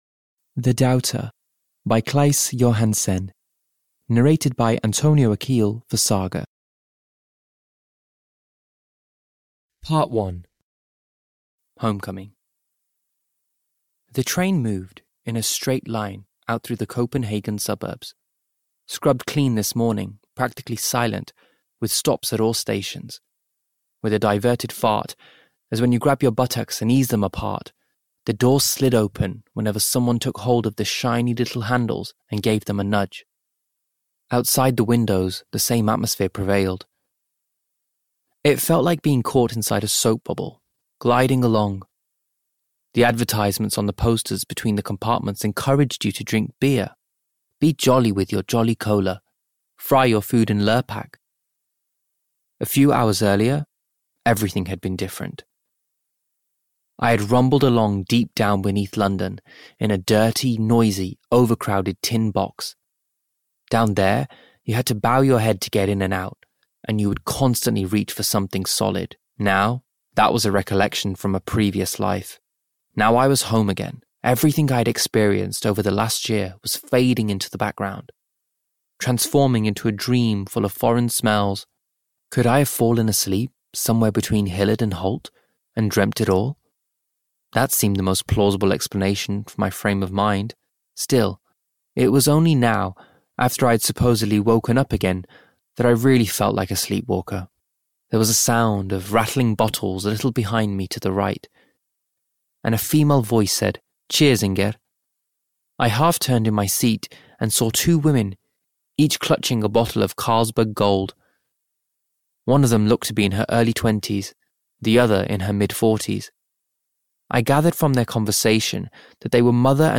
The Doubter (EN) audiokniha
Ukázka z knihy